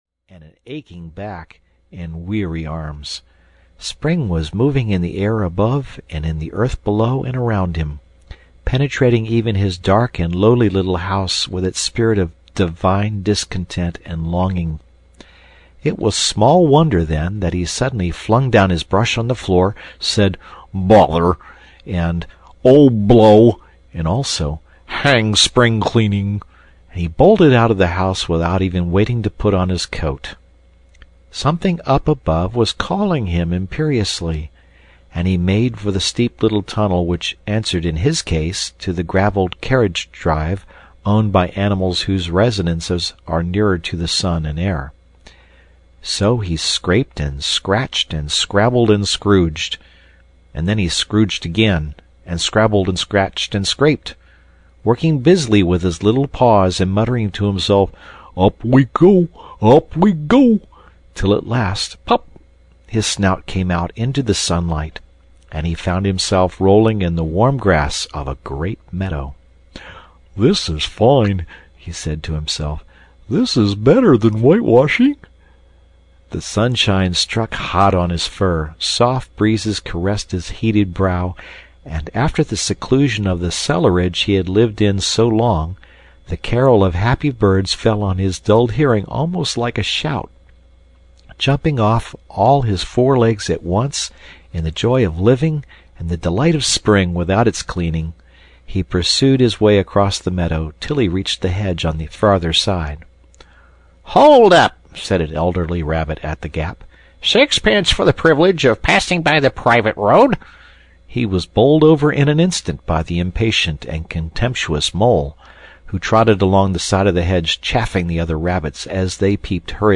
The Wind in the Willows (EN) audiokniha
Ukázka z knihy